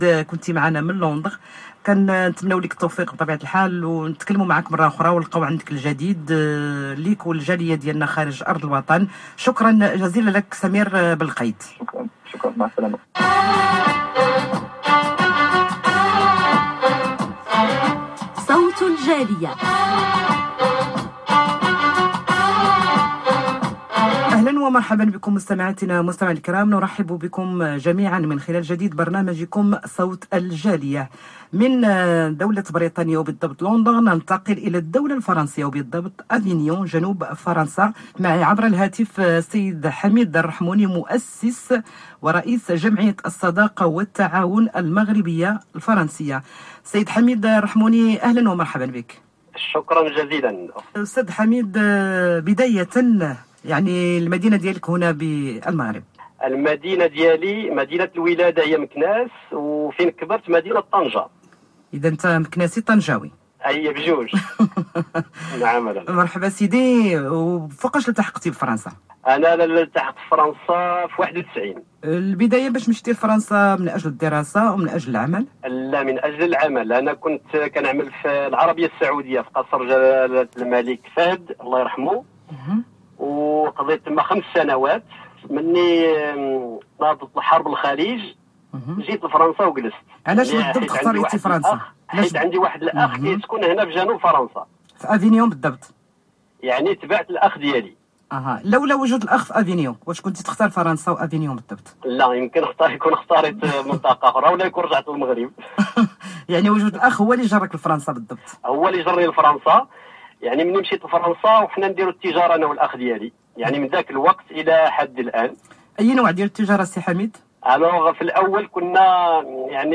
Interview de la radio marocaine SNRT, lors de l'émission "Sawt El Jaliya" :